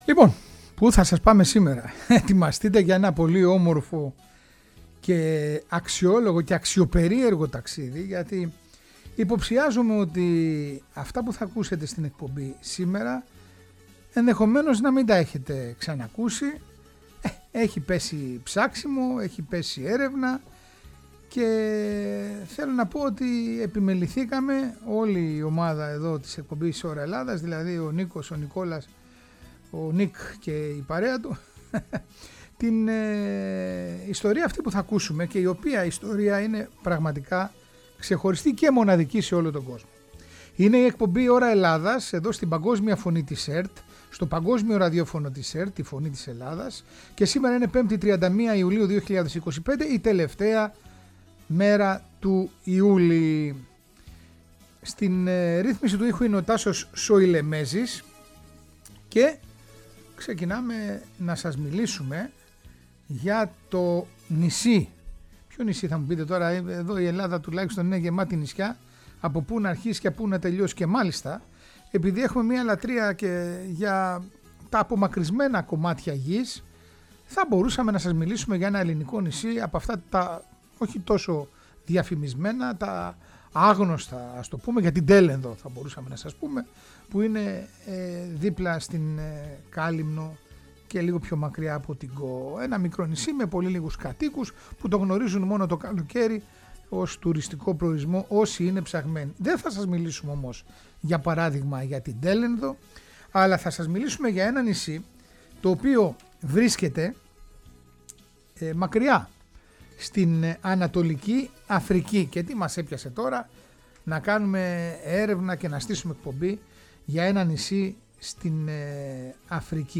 Παράλληλα ακούμε υπέροχες μουσικές από τις δυο χώρες που διεκδικούν το νησί… την Κένυα και την Ουγκάντα.